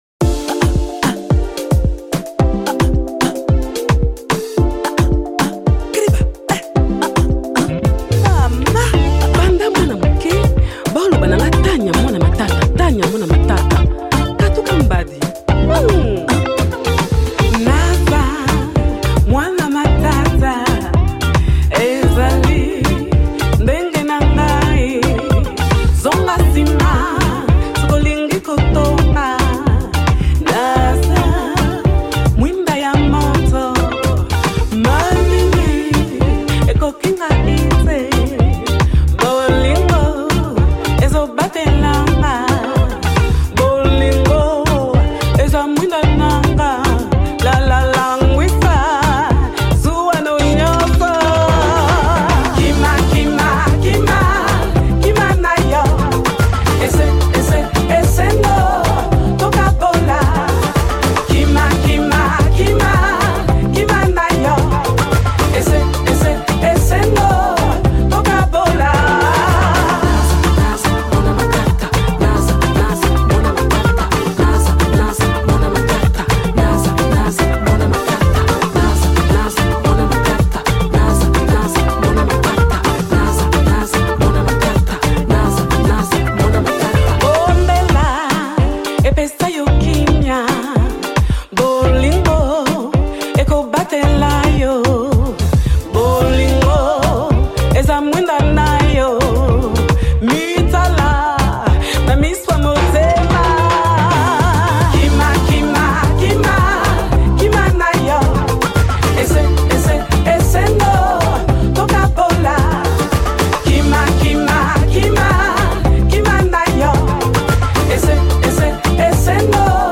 la chanteuse franco-congolaise
un titre plein de groove et et bonnes vibrations.